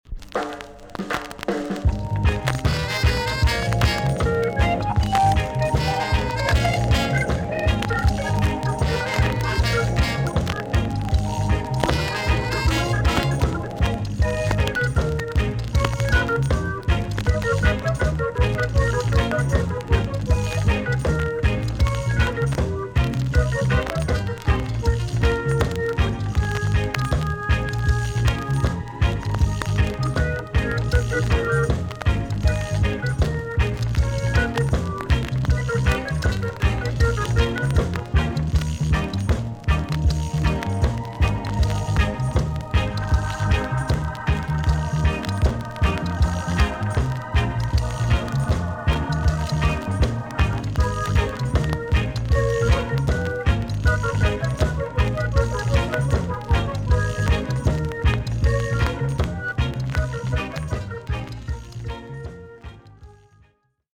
TOP >SKA & ROCKSTEADY
VG ok チリノイズが入ります。